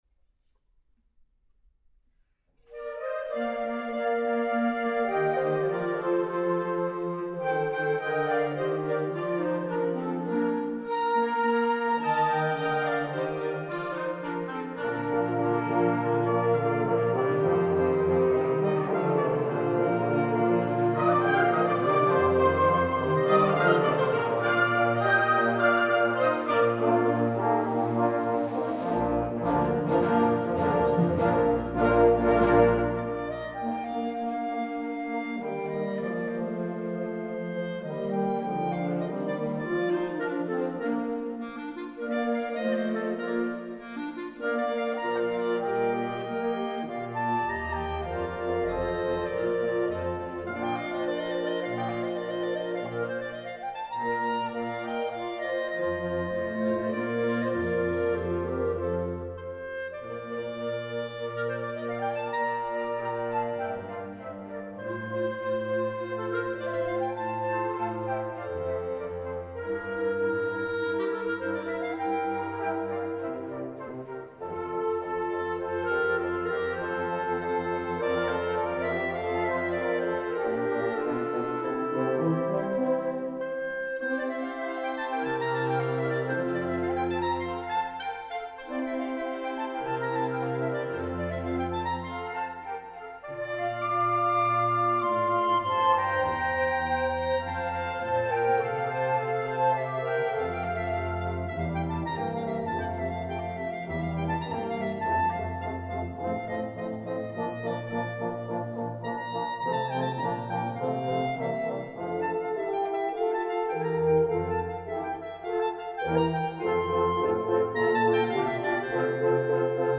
Voicing: Bb Clarinet w/ Band